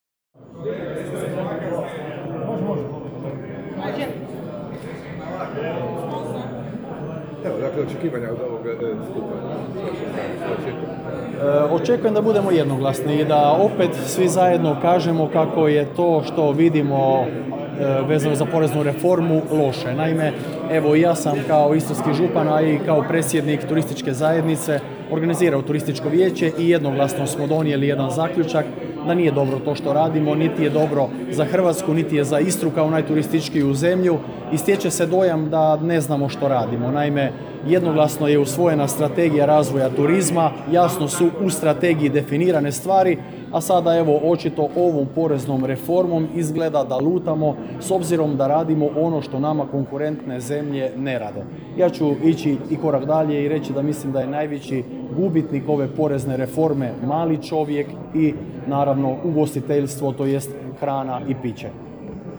U ponedjeljak, 21.11.2016. u pazinskom Spomen domu okupilo se više stotina ugostitelja na skupu protiv povećanja PDV-a u ugostiteljstvu.